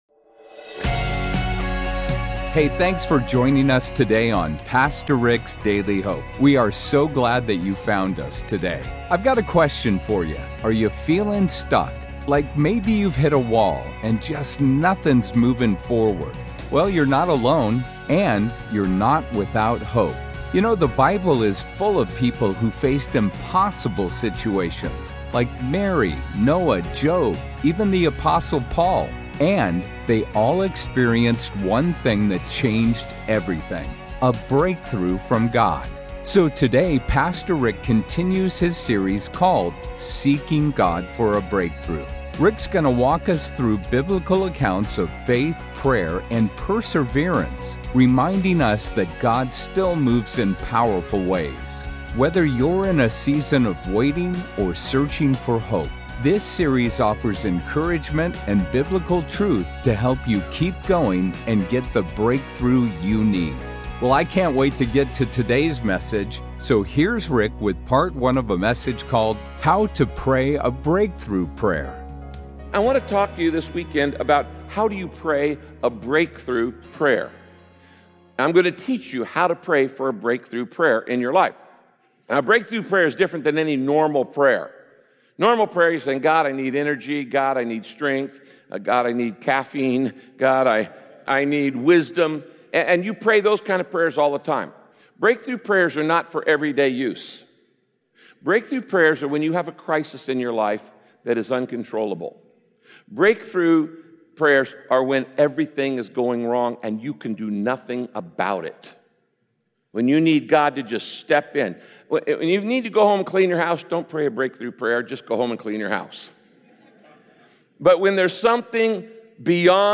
Breakthrough prayers always include gratitude—thanking God in advance for his blessings. In this message, Pastor Rick explains how important it is to have an at…